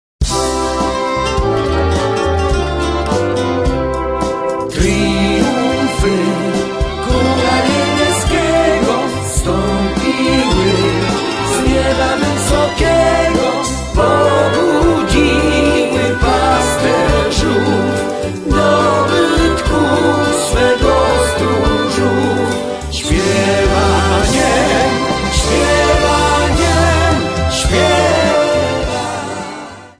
Polish Christmas Carols